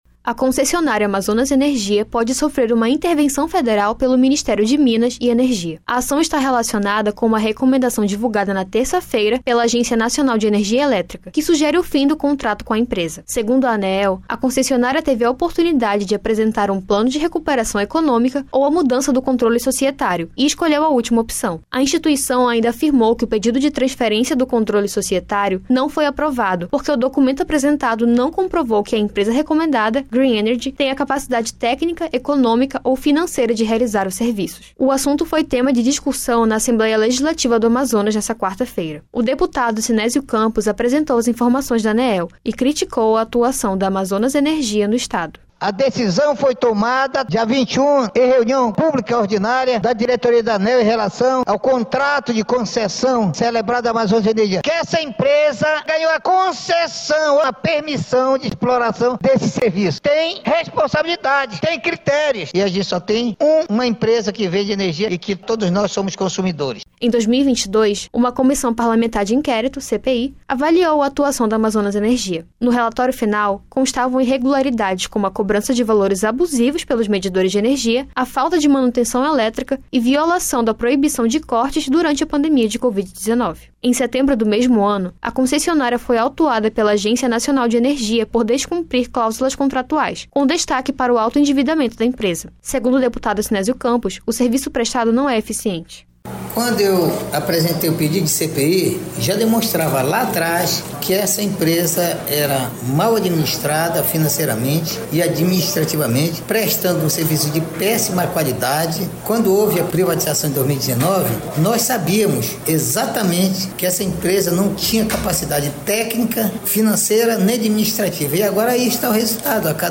Reportagem
O deputado Sinésio Campos (PT) apresentou as informações da Aneel e criticou a atuação da Amazonas Energia no estado.